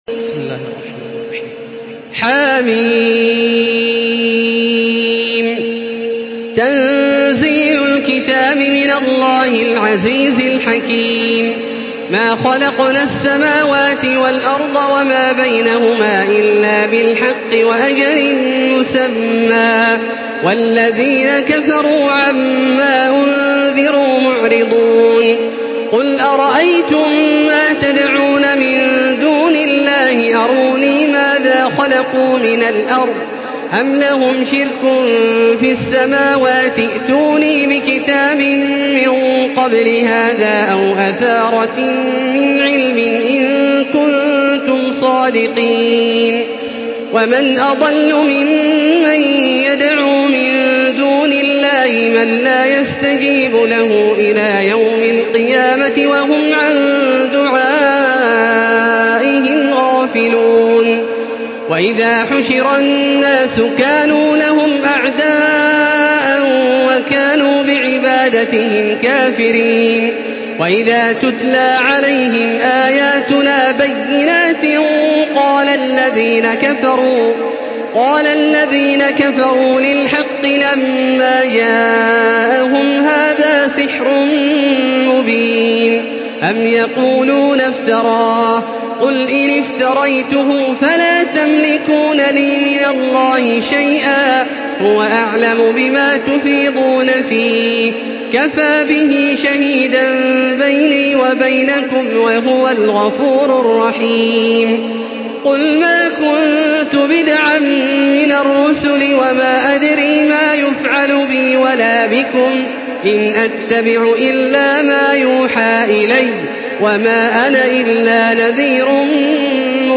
Listen and download the full recitation in MP3 format via direct and fast links in multiple qualities to your mobile phone.
دانلود سوره الأحقاف توسط تراويح الحرم المكي 1435